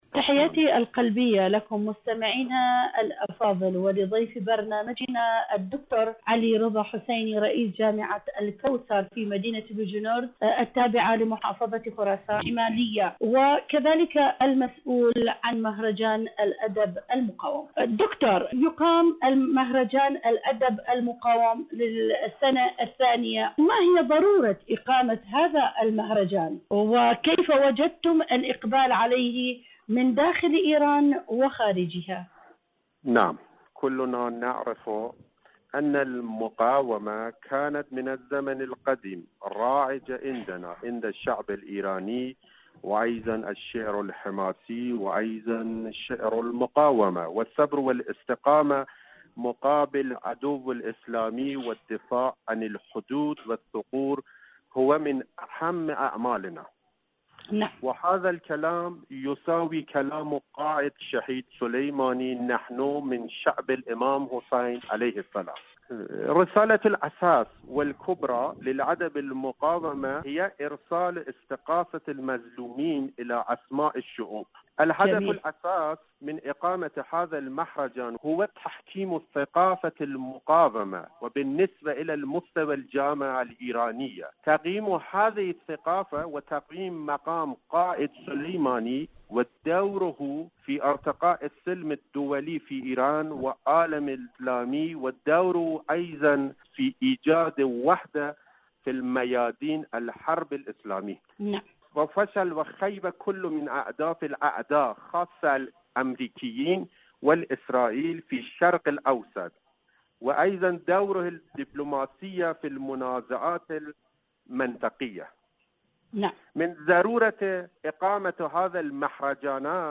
إذاعة طهران-ألوان ثقافية: مقابلة إذاعية